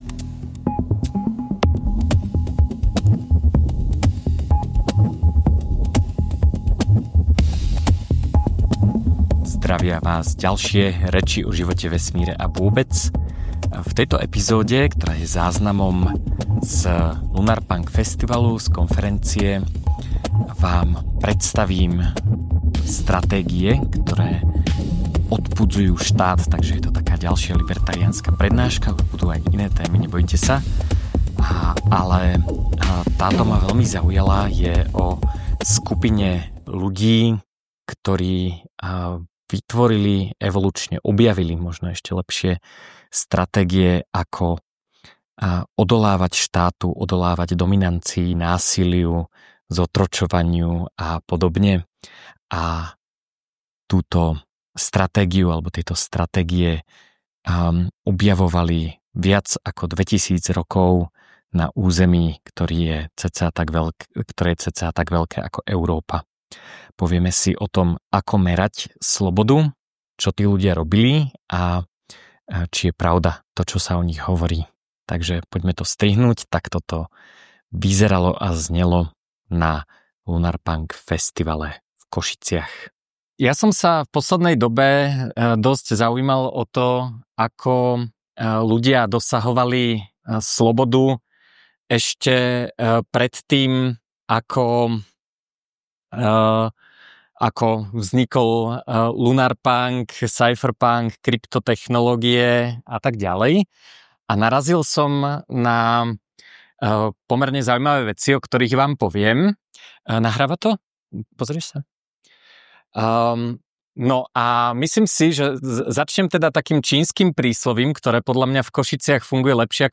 Prednáška skúma evolučnú podstatu štátu – nie ako zámerný plán, ale ako výsledok prirodzeného výberu.